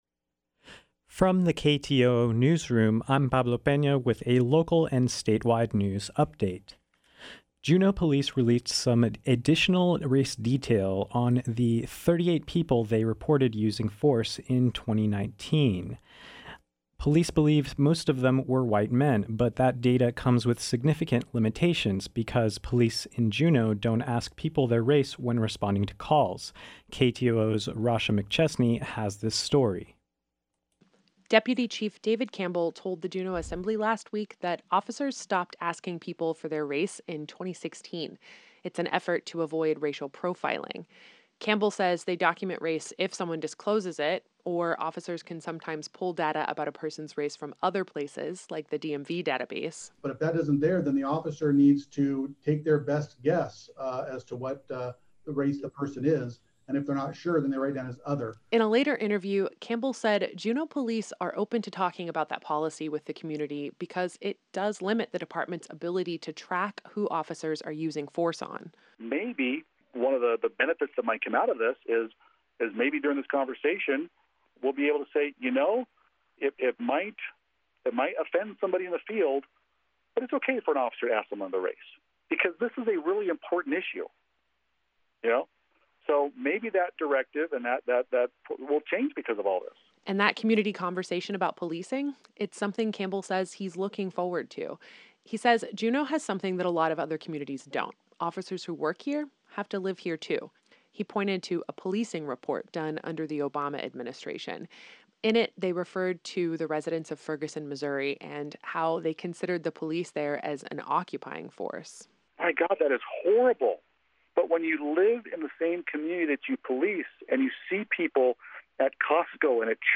Newscast — Tuesday, June 16, 2020